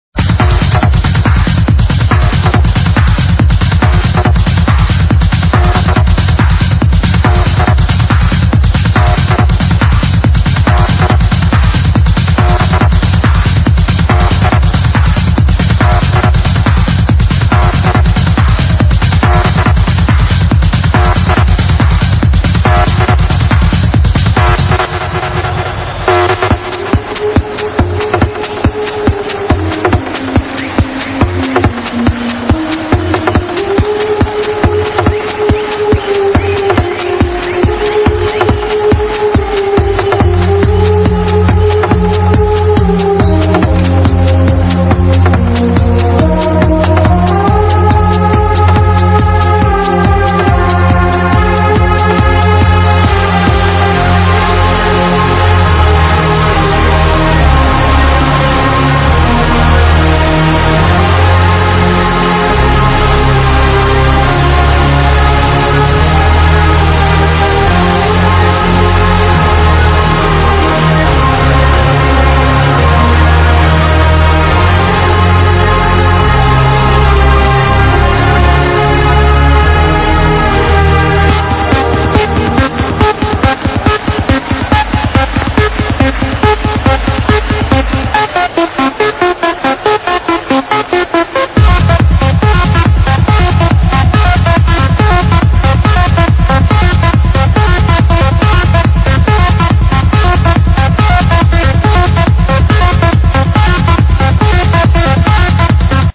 ......reminds me of the original Terminator theme